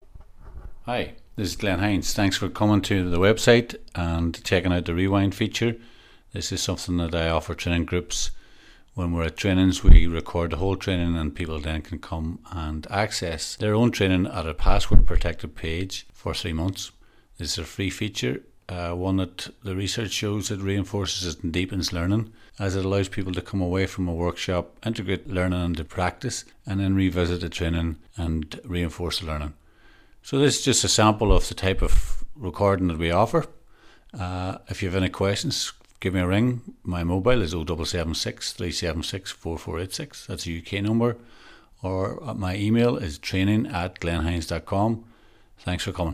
In this REWIND section we provide an indexed audio copy of your training to support your learning after your training is over.